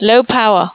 En cas de batterie faible il dit "Low Power" normalement deux fois et ferme.
battery_low.wav